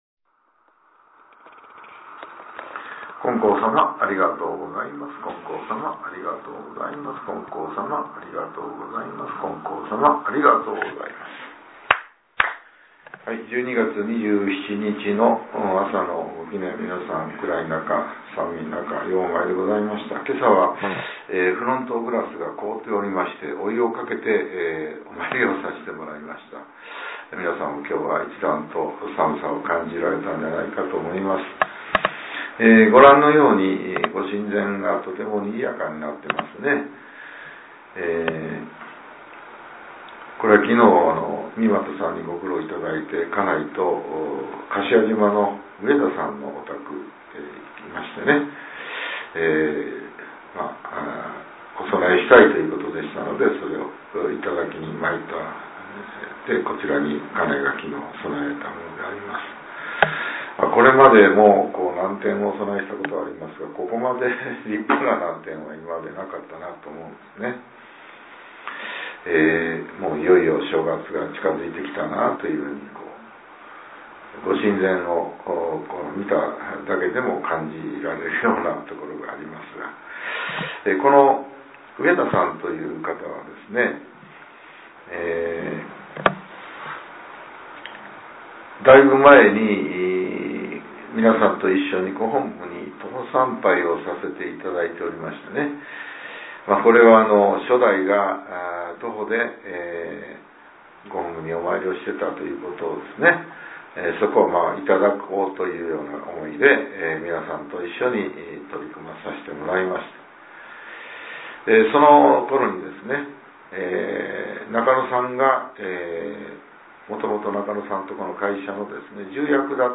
令和７年１２月２７日（朝）のお話が、音声ブログとして更新させれています。